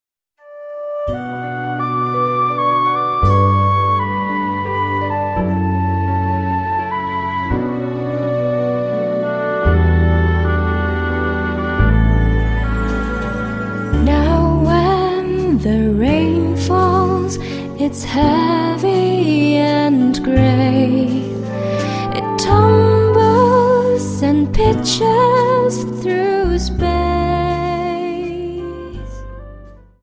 Slow Waltz 28 Song